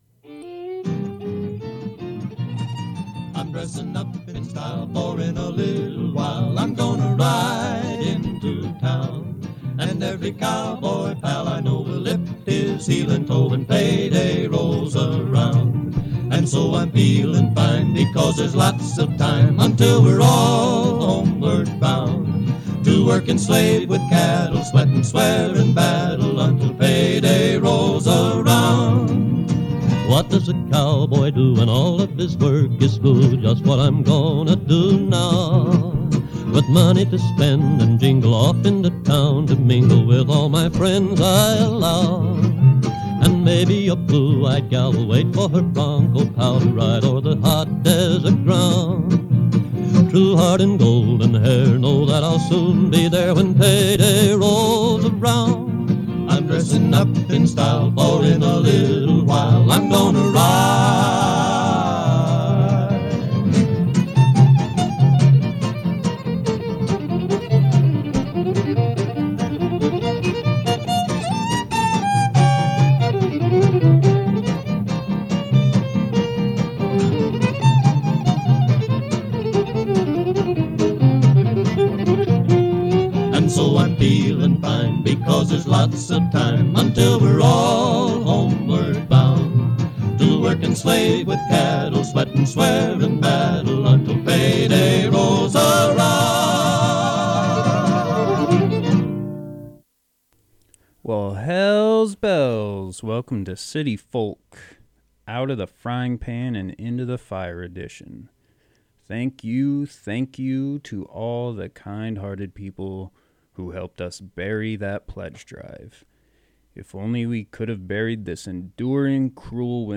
You’re invited to put on your boots and join us as we meander through the wild areas of our modern urban landscape, exploring contemporary and classic Americana, folk, country and elusive material that defies genre. New or artifact, urban or rural – City Folk is a curated field guide for the humble, a study in dirt and bone.